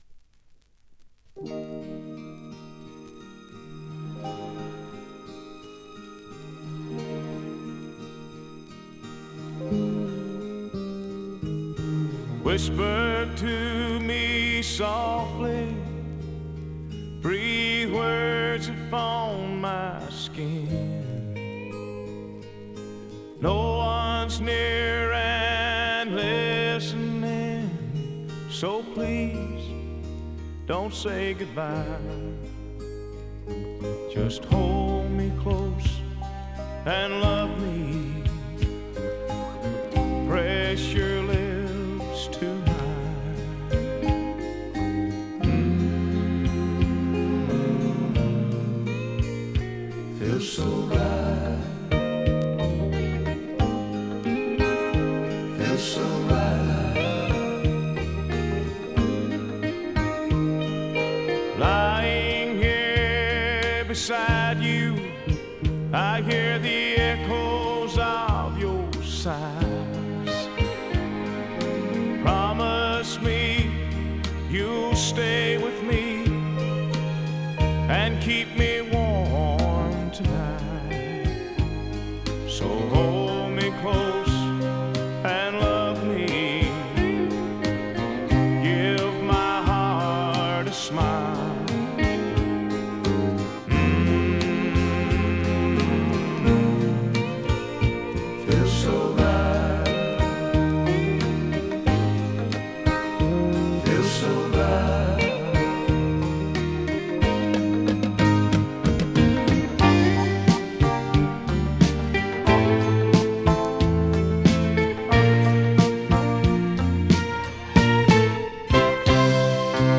американская кантри-рок-группа.